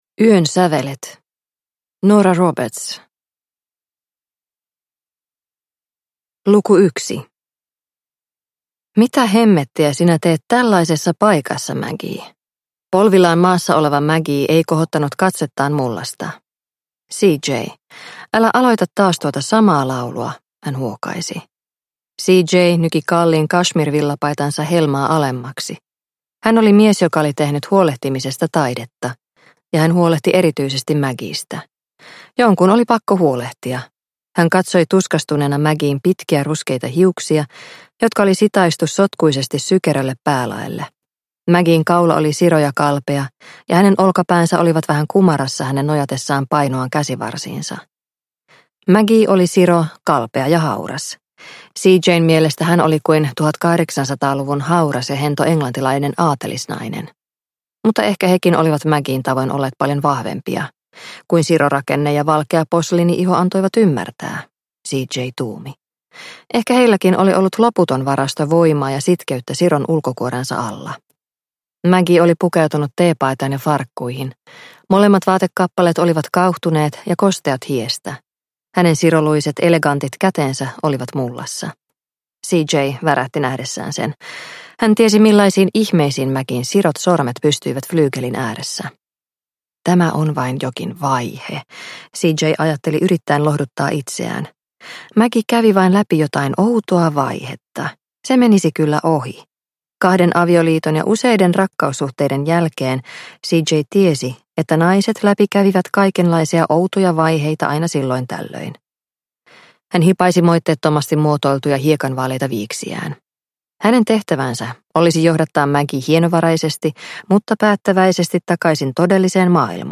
Yön sävelet – Ljudbok